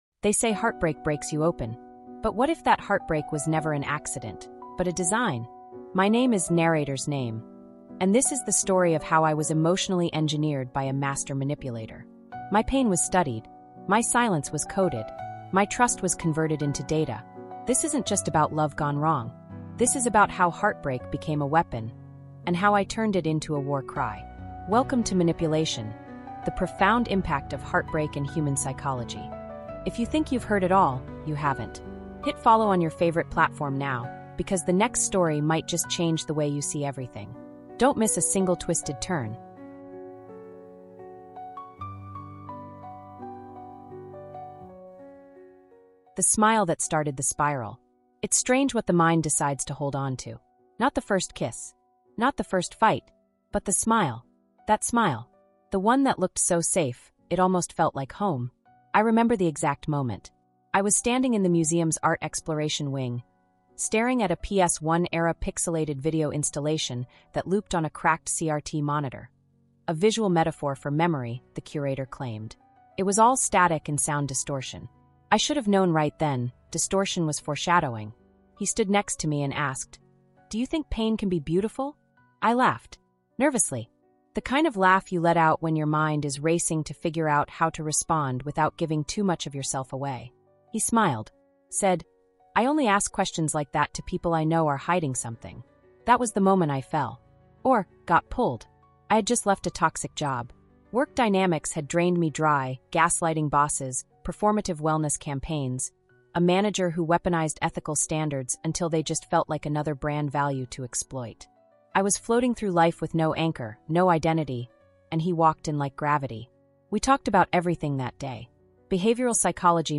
MANIPULATION: The Profound Impact of Heartbreak and Human Psychology is a gripping, emotionally immersive first-person psychological thriller that tears the veil off modern manipulation, influence, and emotional control. Told through the raw voice of a survivor, this seven-part true crime series explores how one man used advanced psychology, persuasion, NLP tactics, and even offshore accounts to turn romance into a dangerous game of mind games, deception, and power.